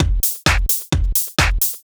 Garage Beat_130.wav